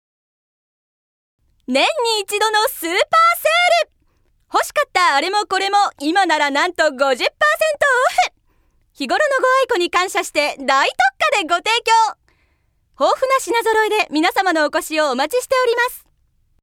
◆量販店の店内放送CM◆